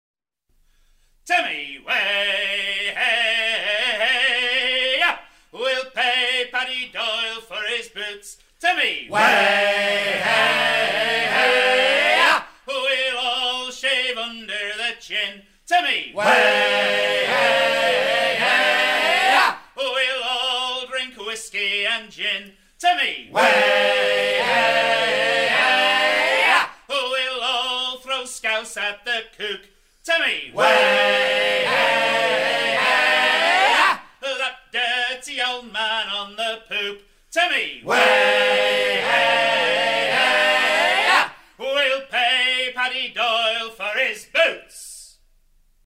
chant à serrer les voiles
Pièce musicale éditée